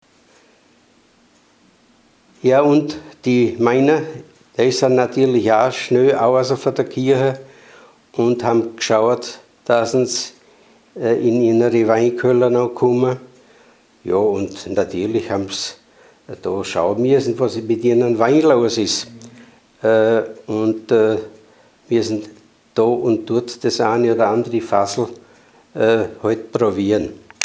Mundart: Wuderscher Dialekt
Man hörte dabei eine gedehnte „ua“ Aussprache.
Unser Budaörser Heimatmuseum besitzt eine Sammlung von Tonaufnahmen in wunderbar gesprochenem Wuderscher Dialekt, den wir in einer Hörprobe präsentieren: